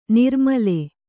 ♪ nirmale